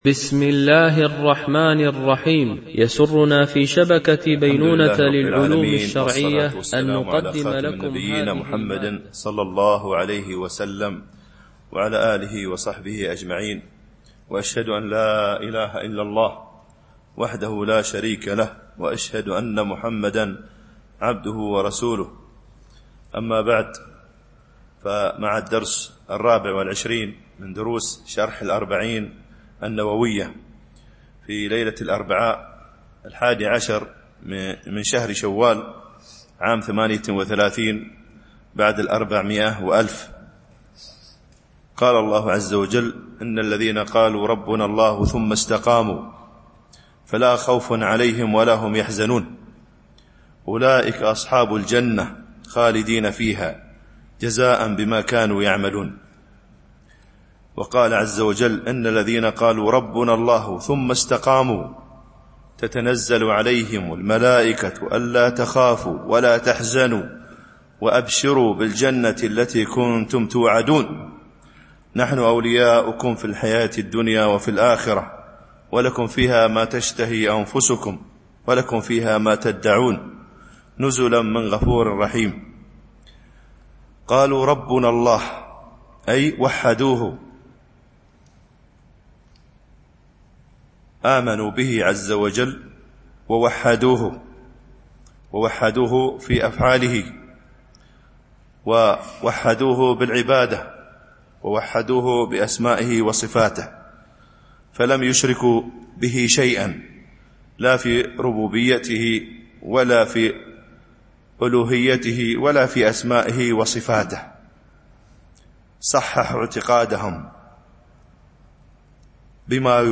شرح الأربعين النووية ـ الدرس 23 (الحديث 10)